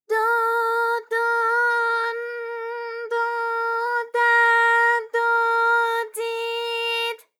ALYS-DB-001-JPN - First Japanese UTAU vocal library of ALYS.
do_do_n_do_da_do_di_d.wav